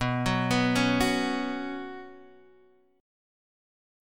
Bsus2 chord